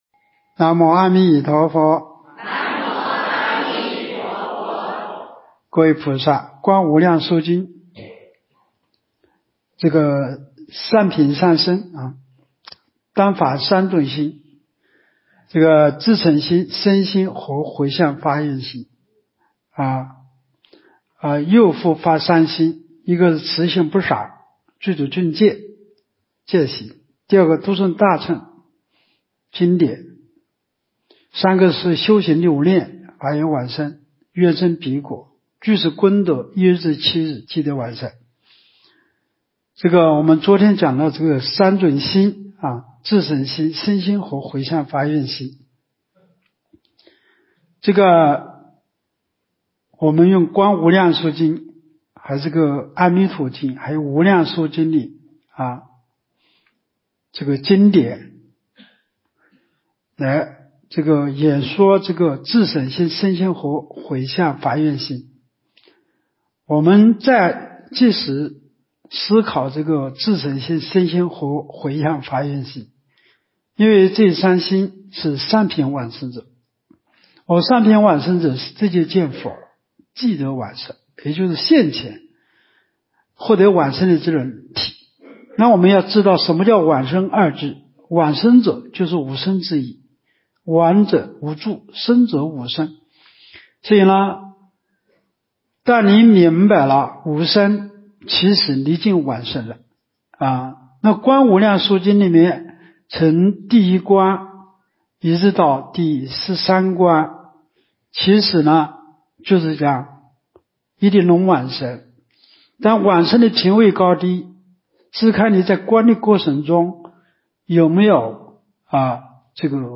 无量寿寺冬季极乐法会精进佛七开示（33）（观无量寿佛经）...